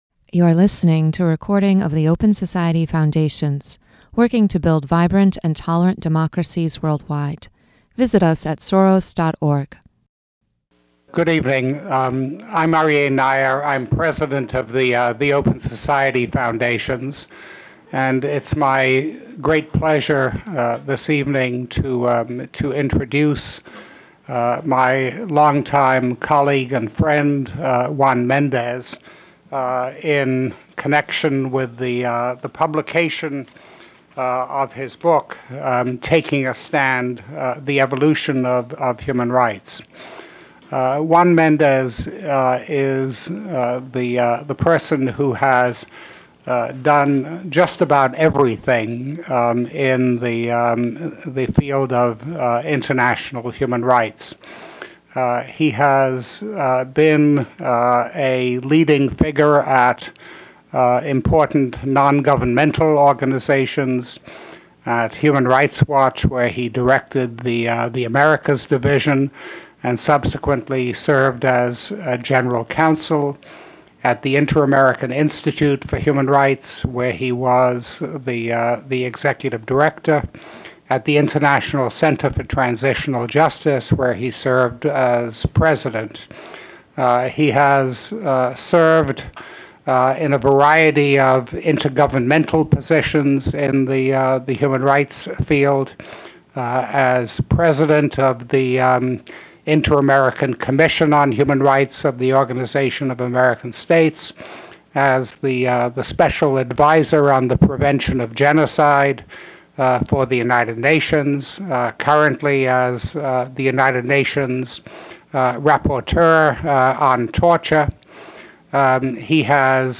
The Open Society Foundations present a conversation with Juan Méndez, whose new book sets forth an authoritative and incisive examination of torture, detention, exile, armed conflict, and genocide.
Méndez offers a new strategy for holding governments accountable for their actions, providing an essential blueprint for different human rights groups to be able to work together to effect change. Aryeh Neier, president of the Open Society Foundations, introduces the event.